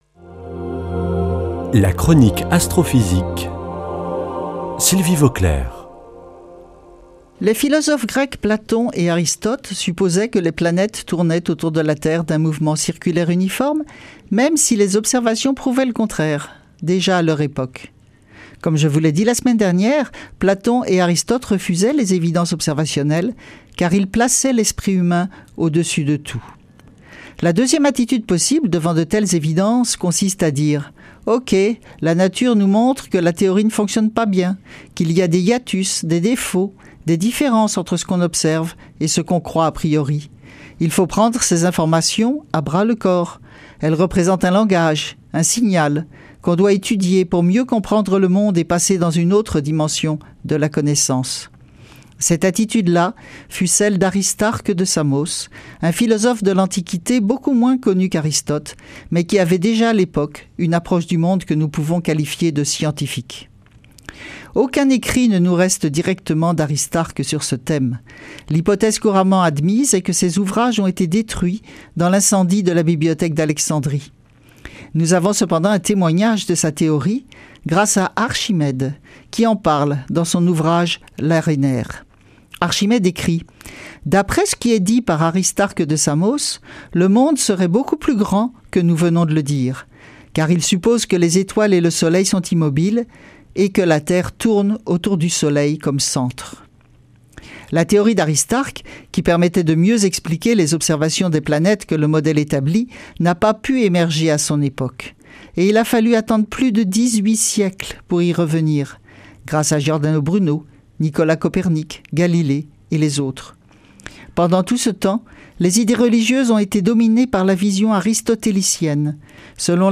Chronique Astrophysique